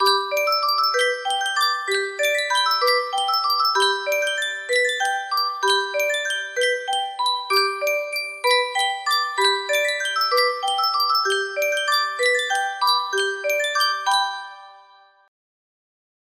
Sankyo Music Box - Arirang AY music box melody
Full range 60